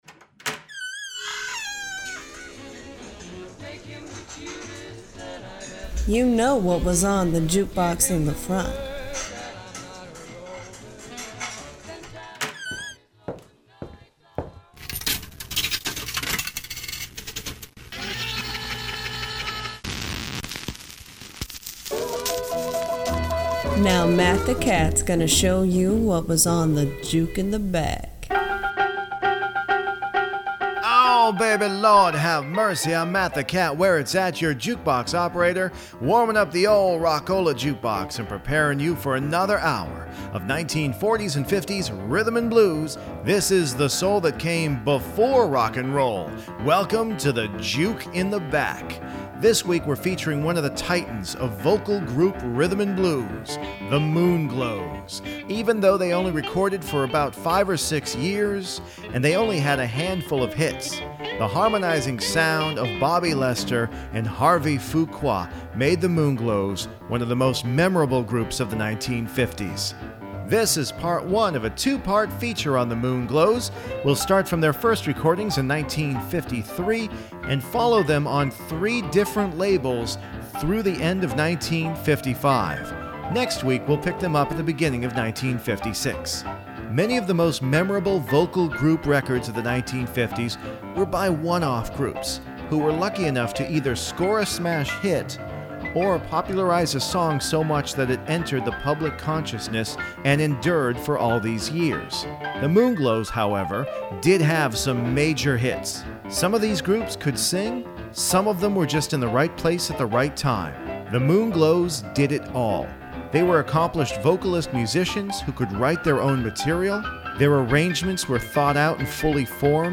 Vocal harmony doesn’t get much sweeter than this, so don’t miss one note of this week’s “Juke In The Back” radio program.